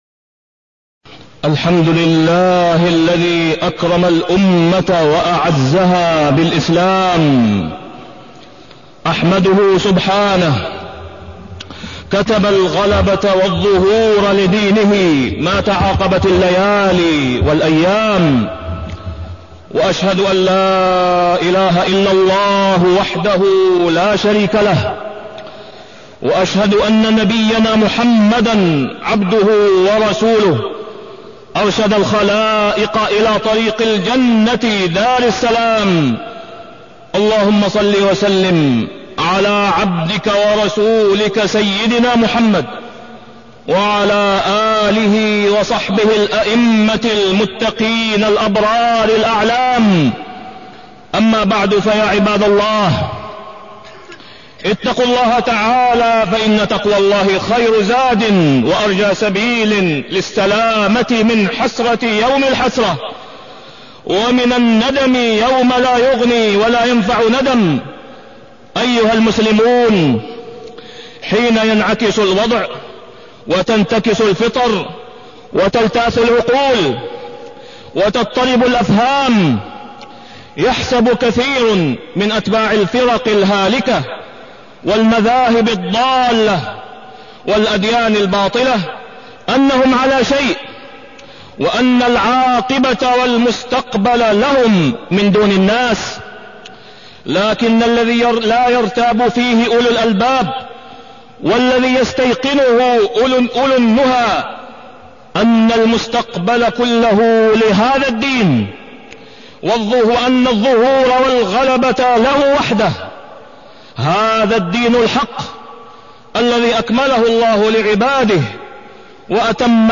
تاريخ النشر ٦ جمادى الأولى ١٤٢٢ هـ المكان: المسجد الحرام الشيخ: فضيلة الشيخ د. أسامة بن عبدالله خياط فضيلة الشيخ د. أسامة بن عبدالله خياط المستقبل لهذا الدين